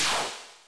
BombHIT.wav